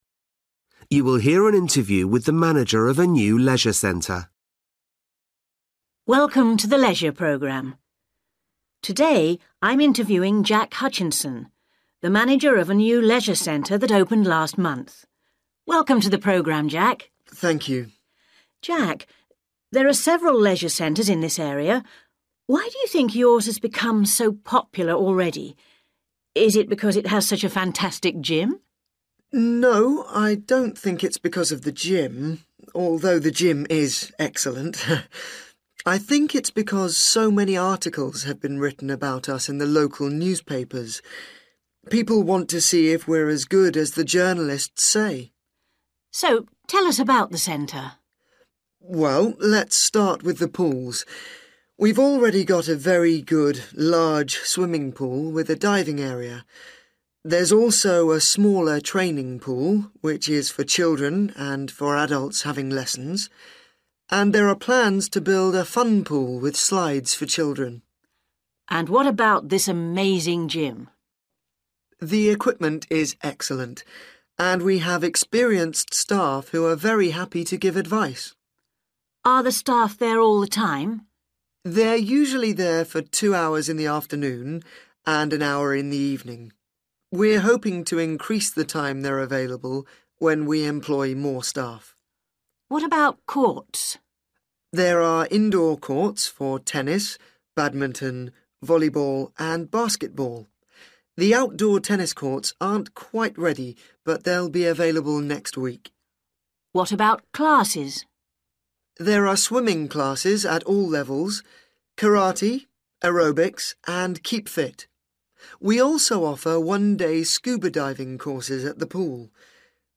You will hear an interview with the manager of a new leisure centre.